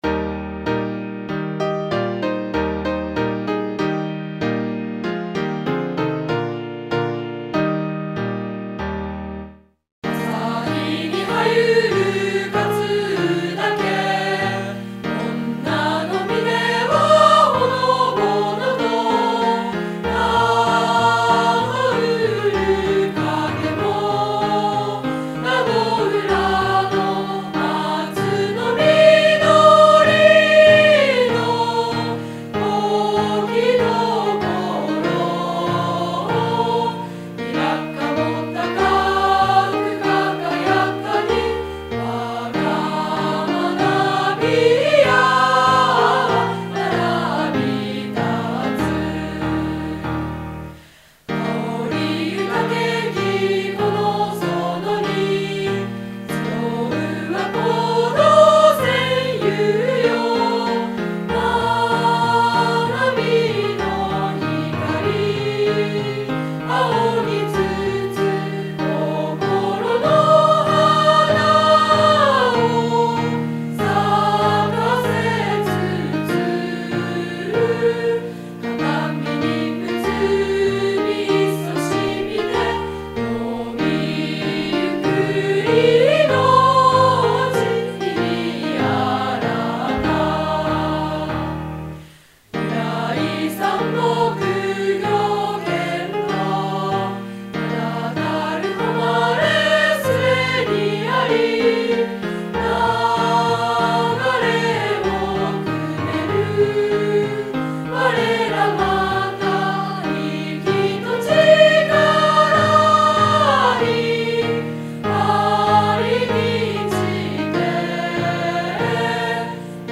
校章・校歌